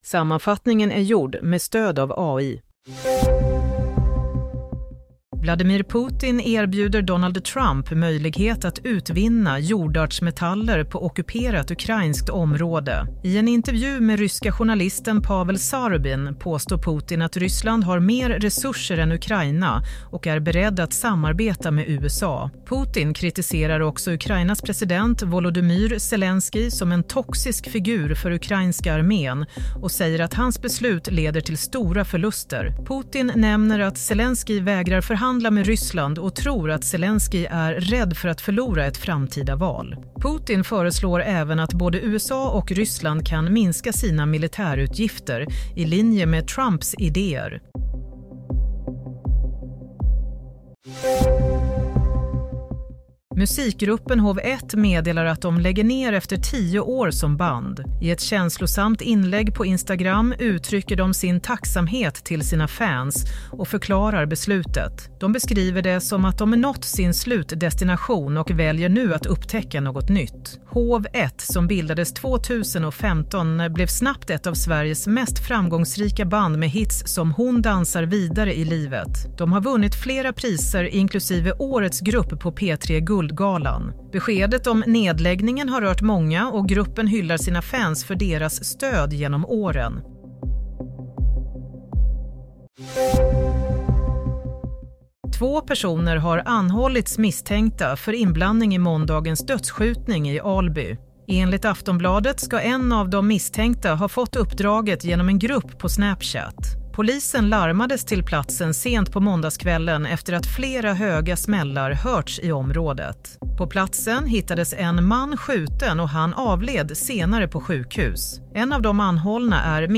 Nyhetssammanfattning - 25 februari 16:00